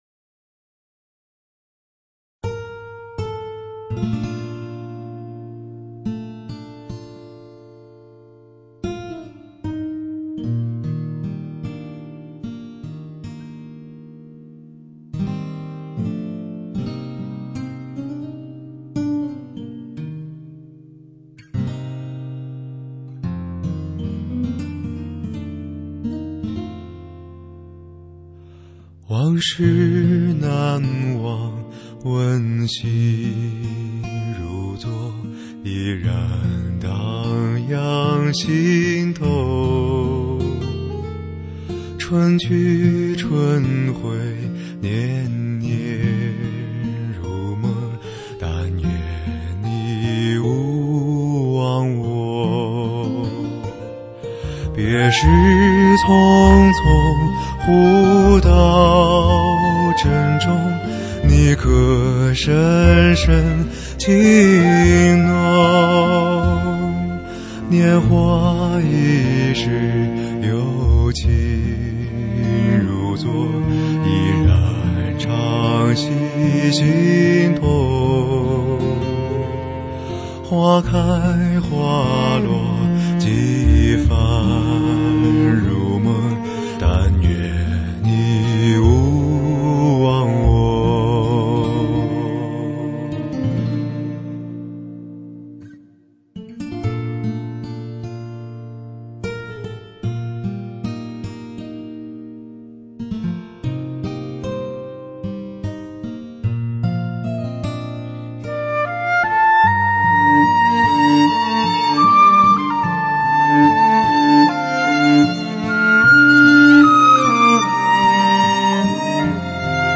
听听上帝赐予他那磁性的嗓音，